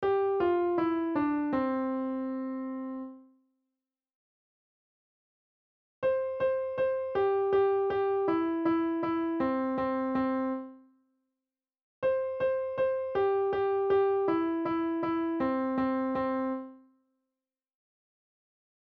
On the piano, play Row, Row Row Your Boat